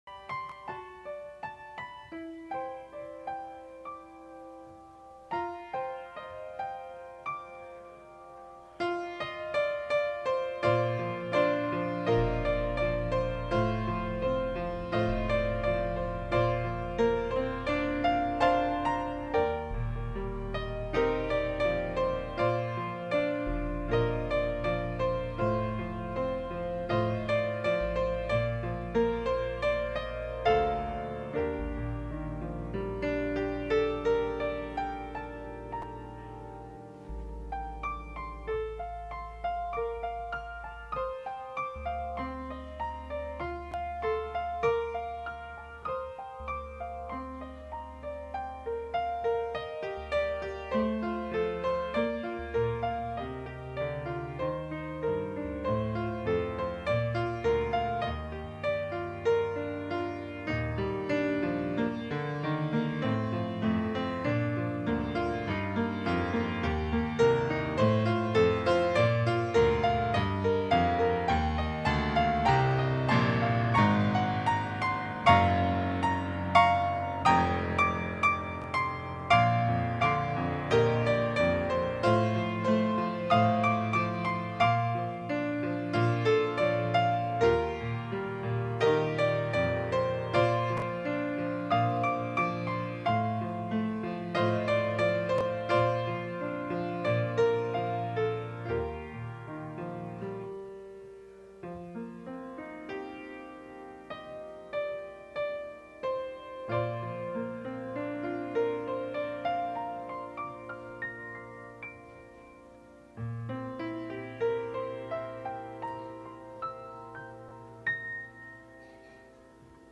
Our Cadets and their Leaders will participate in the service.
January 30, 2022 (Morning Worship)